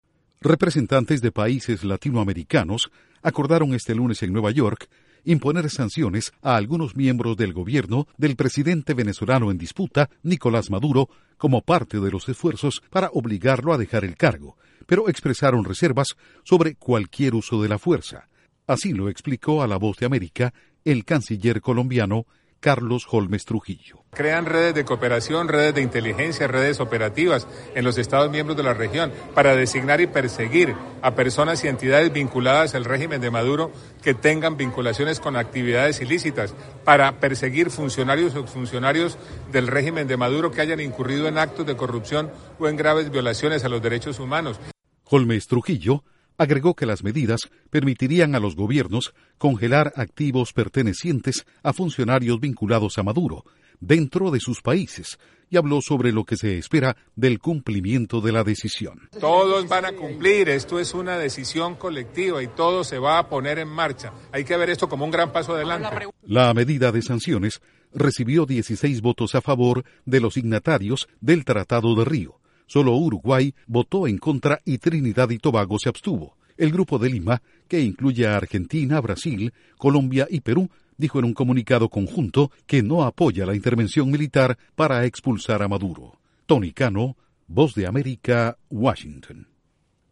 Duración: 1:30 Incluye 2 audios de Carlos Holmes Trujillo/Canciller Colombia